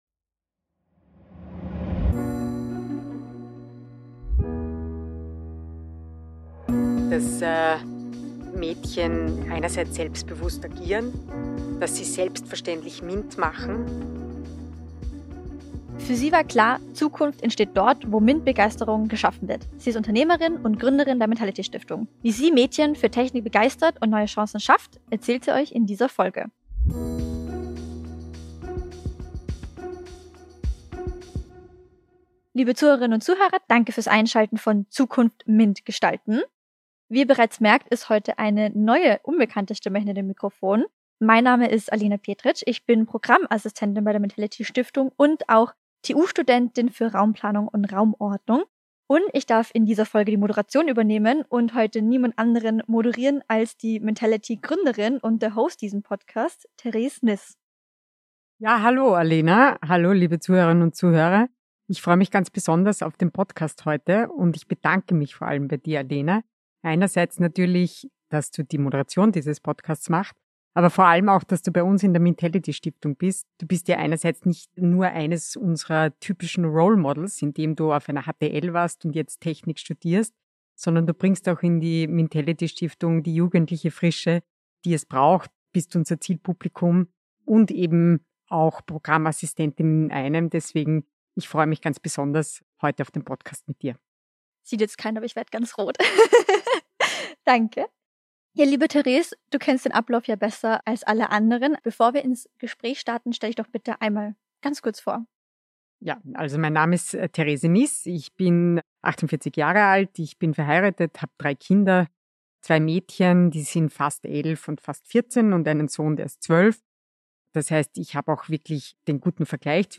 Ein Gespräch über das, was sie antreibt – und warum es ihr nicht egal ist, wie die Zukunft aussieht.